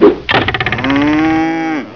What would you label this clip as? cow sounds from: Cow Sounds cow.wav